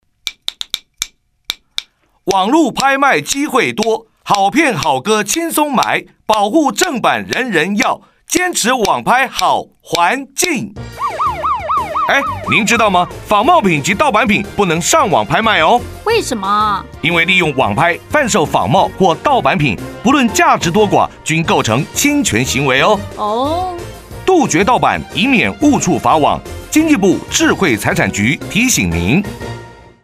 （二）運用全國各廣播電台播放保護著作權宣導廣告及「營業場所著作權篇」50秒宣導短劇等共計1,260檔次以上，全面建立民眾保護智慧財產權之正確認知。